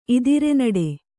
♪ idirenaḍe